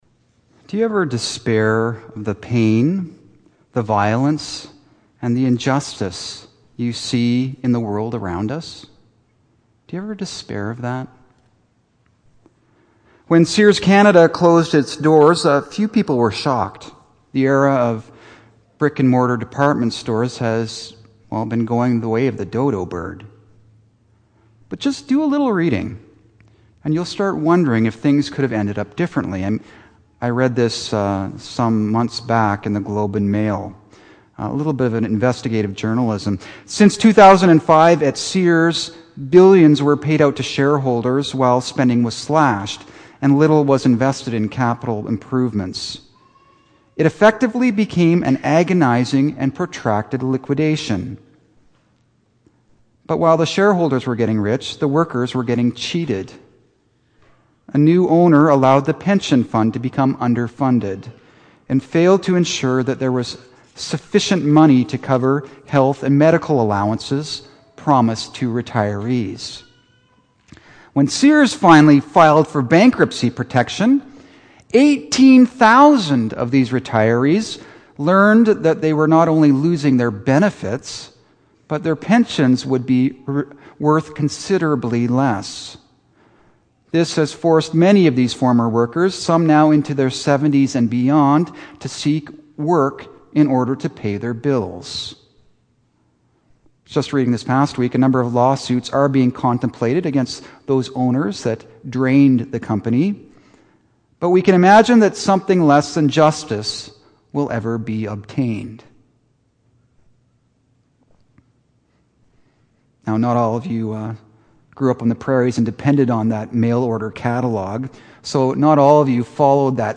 Habakkuk 1:1-4; 2:1-4; 3:3-6, 17-19 Sermon